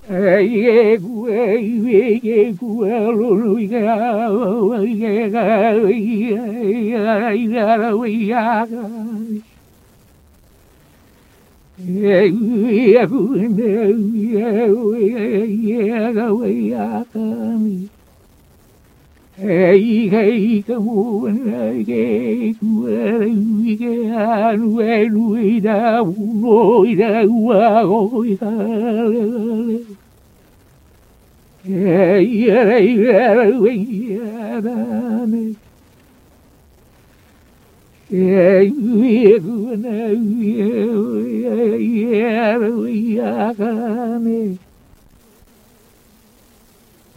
Hula with ipu